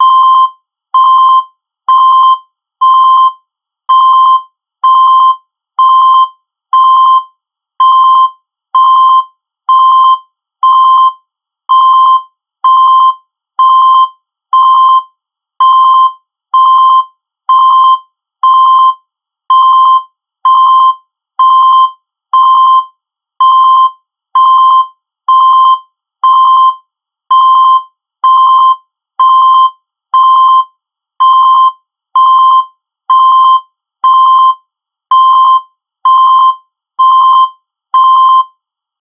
目覚まし音は、朝の起床時に必要な役割を果たす重要な音です。